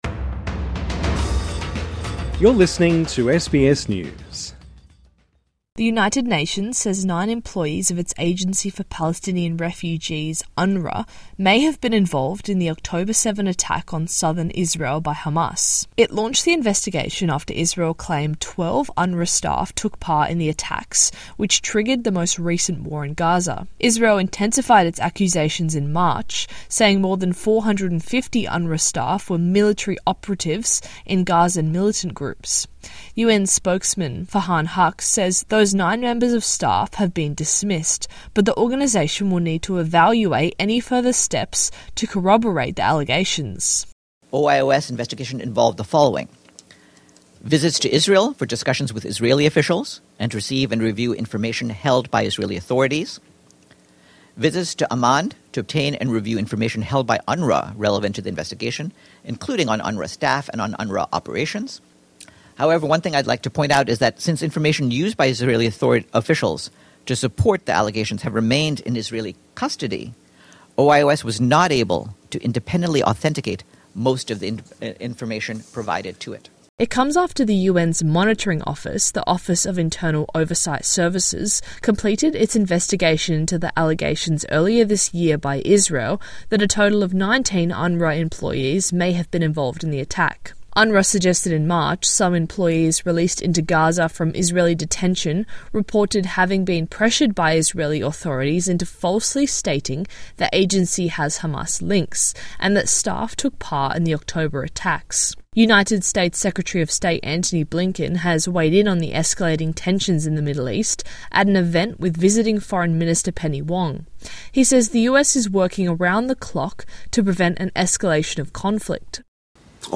SBS News In Depth